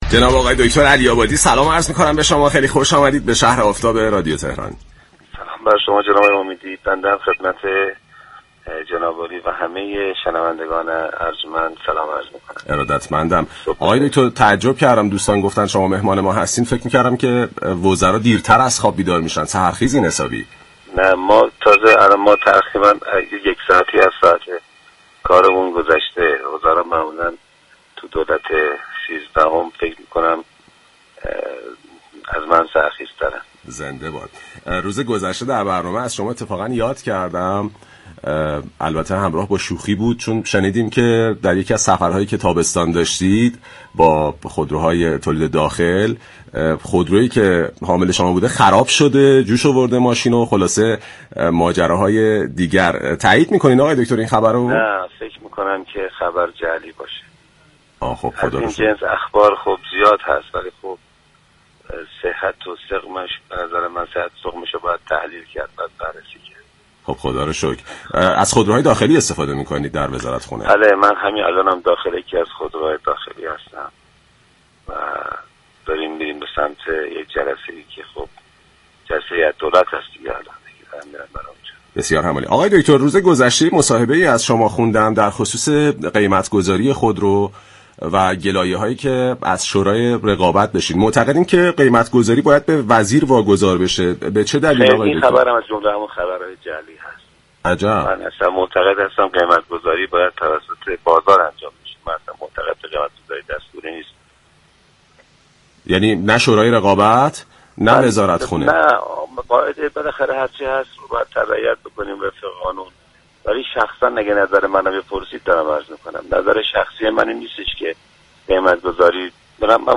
عباس علی‌آبادی وزیر صمت در گفت و گو با «شهر آفتاب» 19 مهر 1402 اظهار داشت